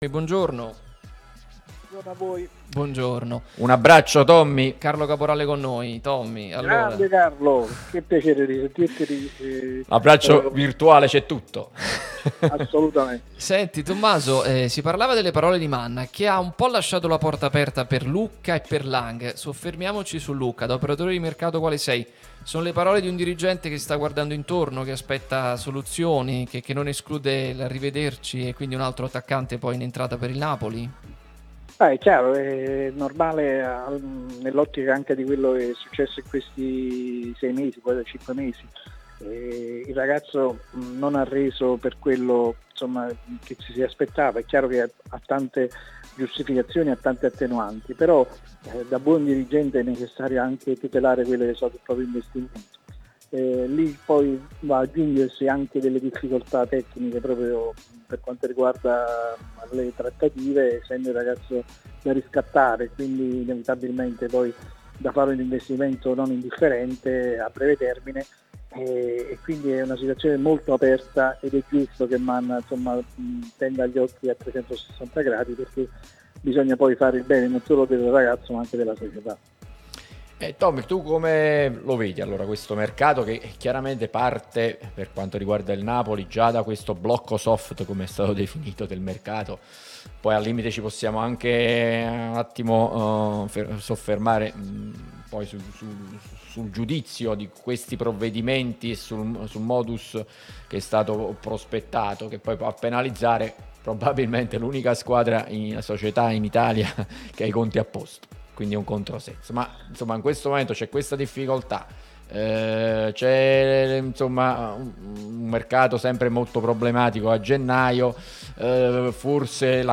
operatore di mercato